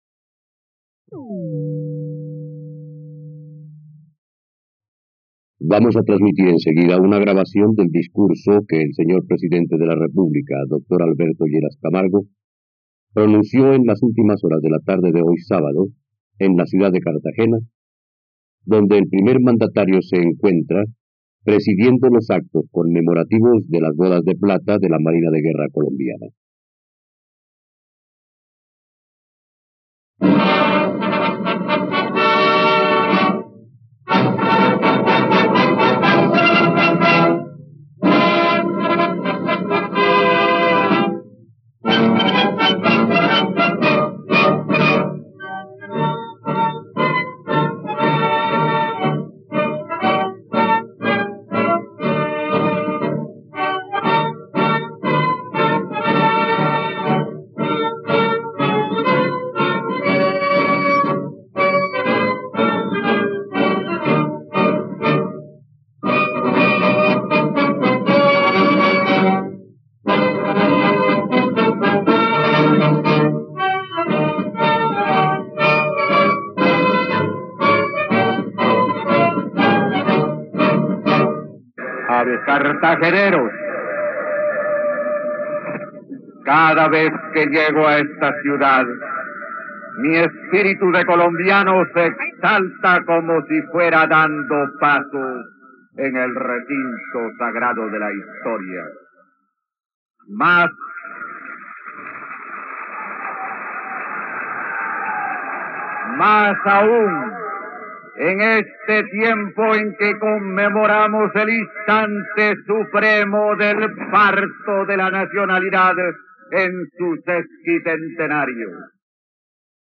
..Escucha ahora el discurso de Alberto Lleras Camargo sobre la crisis de los partidos, el 2 de julio de 1960, en la plataforma de streaming RTVCPlay.
En Cartagena, durante las bodas de plata de la Marina, el presidente Alberto Lleras Camargo defiende el Frente Nacional como respuesta a la violencia mientras advierte sobre las divisiones partidistas que amenazan la estabilidad de la Nación.